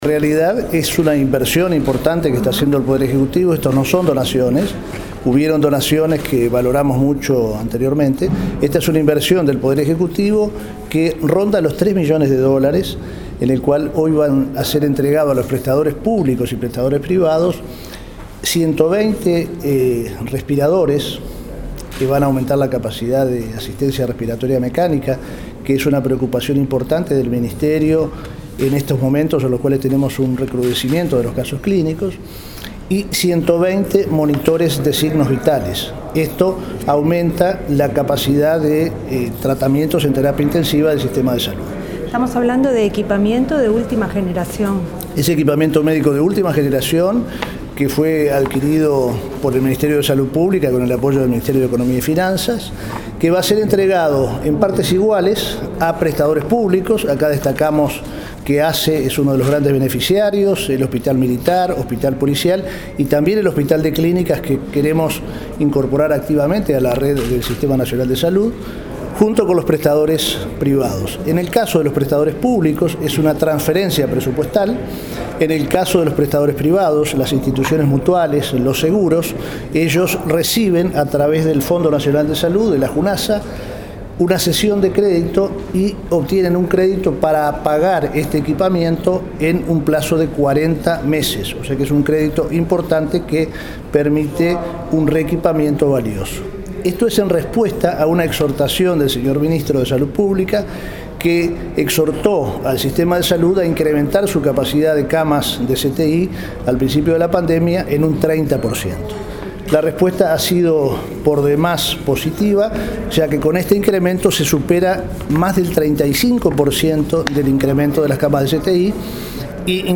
Palabras del presidente de la Junta Nacional de Salud (Junasa), Luis González Machado.